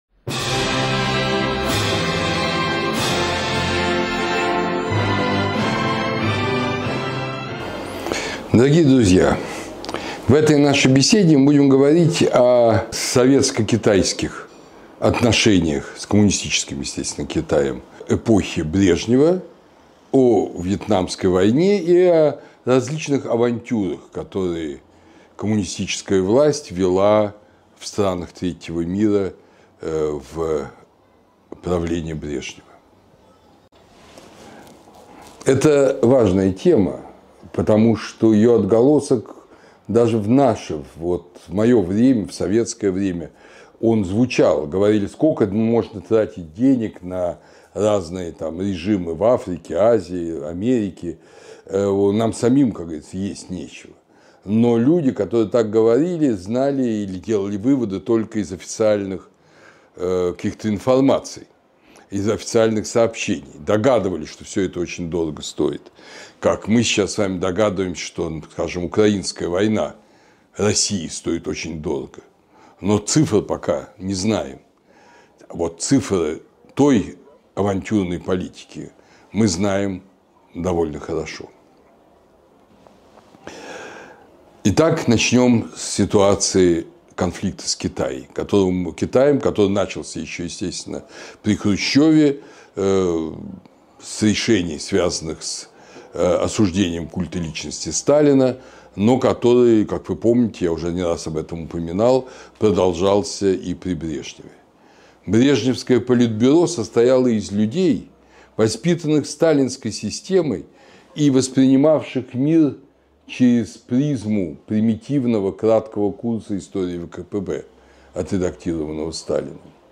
Лекцию читает Андрей Зубов